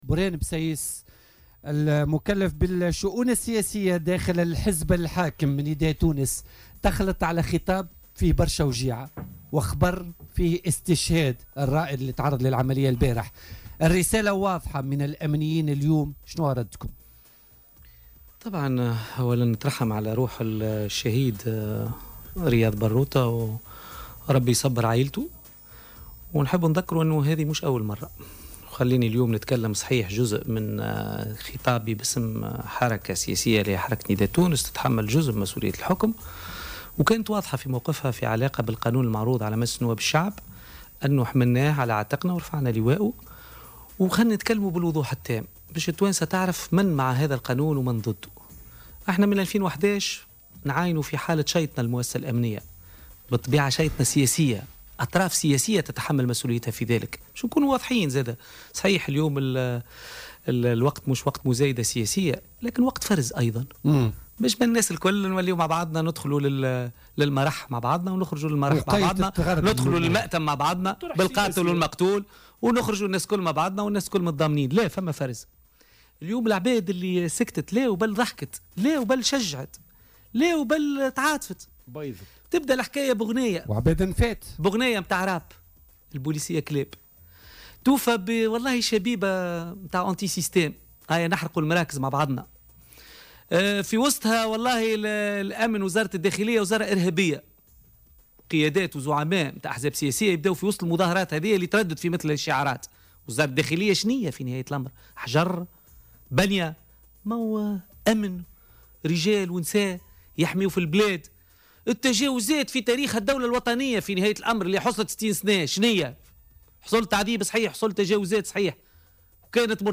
قال المكلّف بالشؤون السياسية بحزب نداء تونس برهان بسيس خلال استضافته اليوم في"بوليتيكا"، إن أطراف سياسية ودكاكين حقوقية ترفض باسم الدفاع عن حقوق الإنسان، تمرير مشروع قانون حماية الأمنيين.